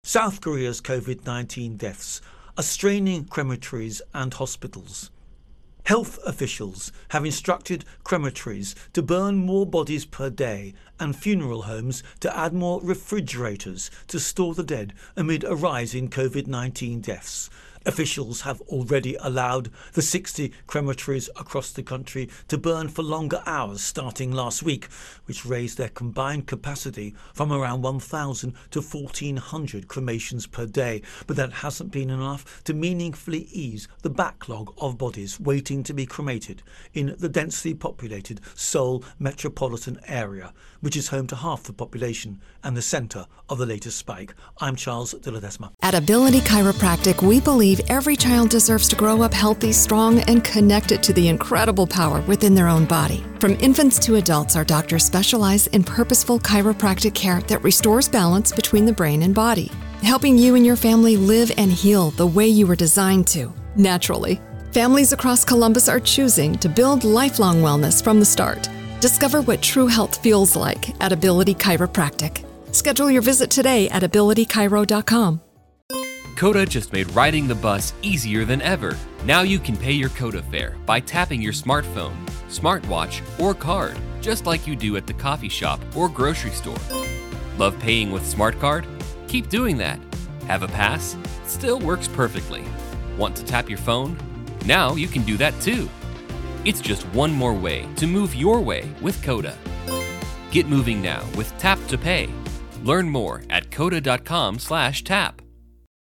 Virus Outbreak South Korea Intro and Voicer